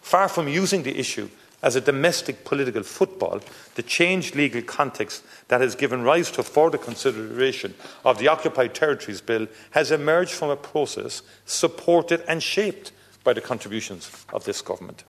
Tánaiste, Micheál Martin, says there are legal rules in place following ICJ rulings, and they must allow time for amendments: